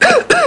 Cough Sound Effect
Download a high-quality cough sound effect.
cough.mp3